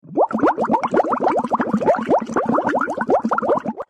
puzyri_24639.mp3